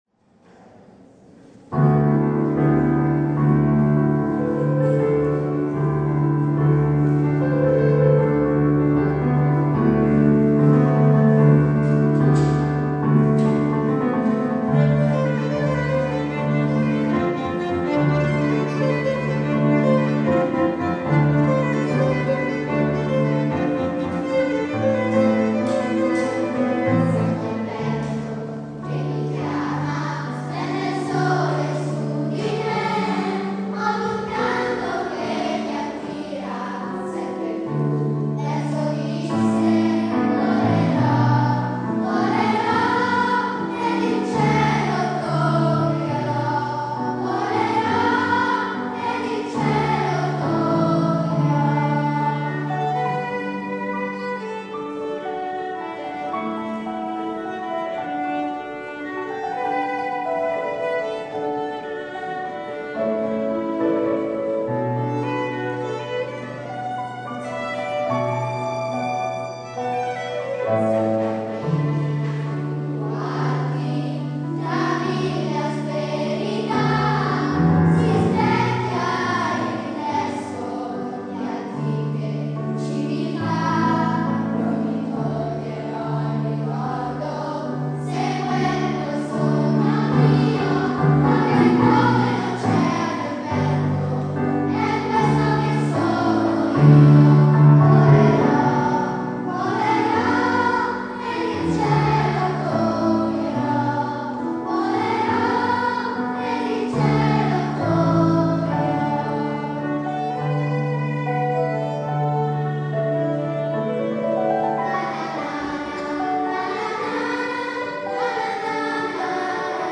S. Gaudenzio church choir Gambolo' (PV) Italy
3 giugno - Pieve di Sant'Eusebio
- La rassegna dei cori